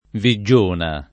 [ vi JJ1 na ]